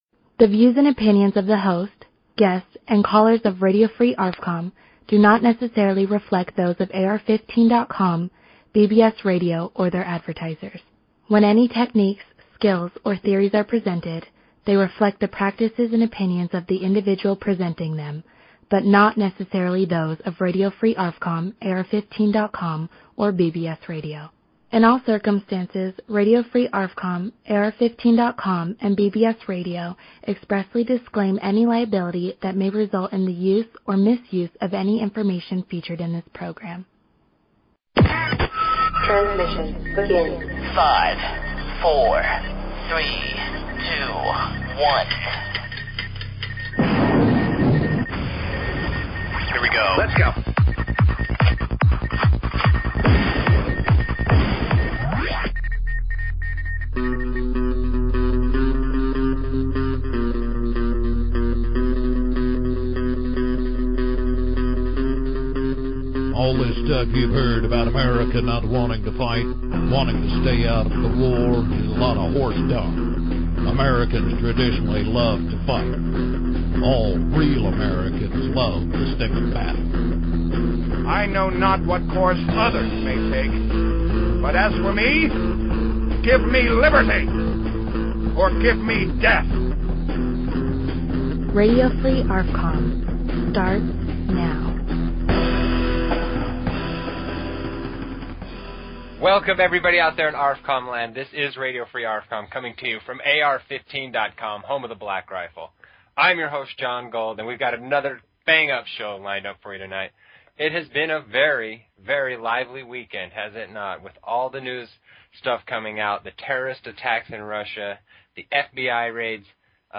Talk Show Episode, Audio Podcast, Radio_Free_ARFCOM and Courtesy of BBS Radio on , show guests , about , categorized as